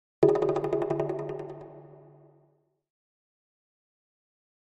Jungle Drums In The Middle Of The Jungle - Slow Down Thin Hits 2